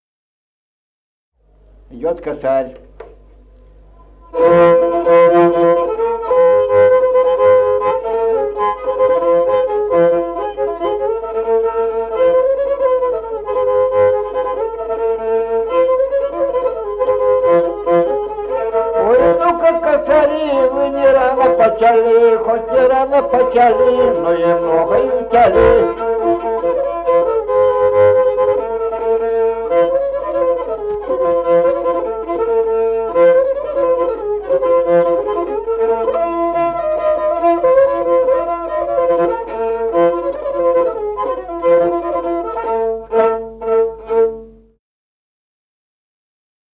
Музыкальный фольклор села Мишковка «Косарь», репертуар скрипача.